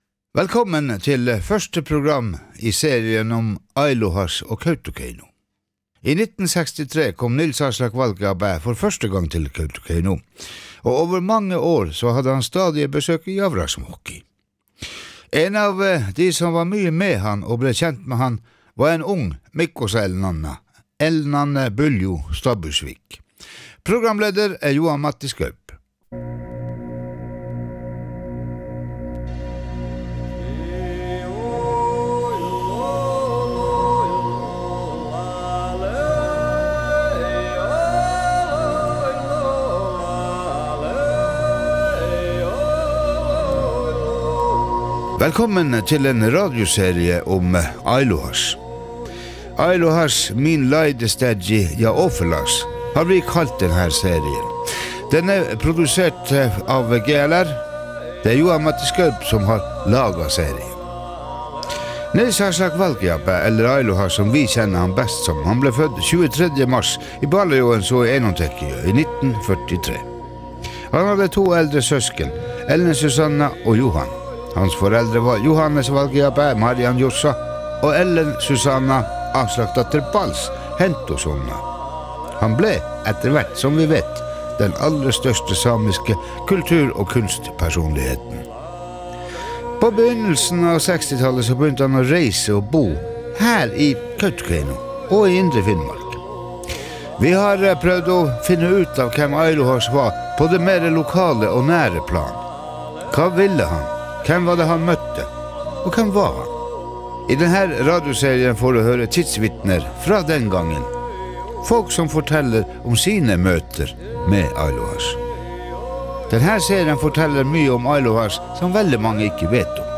Fredag 10 Januar startet en ny programserie produsert av GLR om multikunstneren Nils Aslak Valkeapää, bedre kjent som Aillohaš. Serien vil gå over 6 episoder der vi har snakket med folk som traff kunstneren første gang han kom til Kautokeino og i årene frem til hans bortgang.